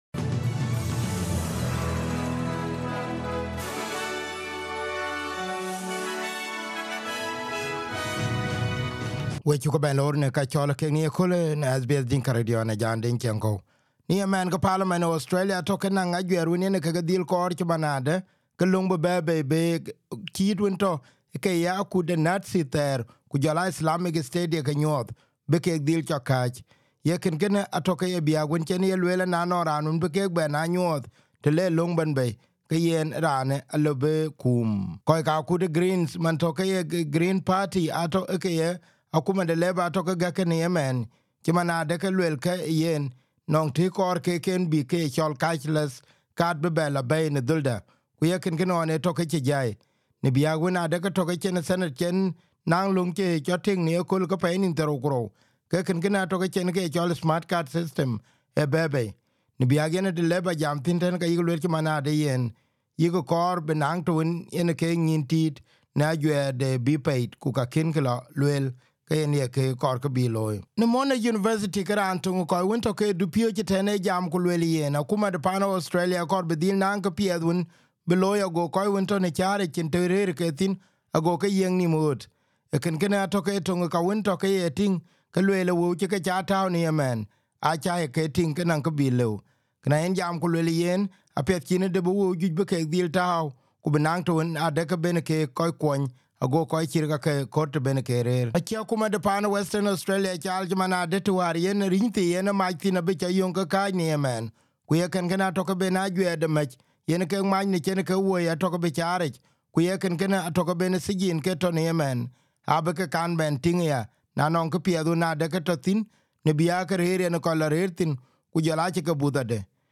SBS Dinka News Flash 22/06/2023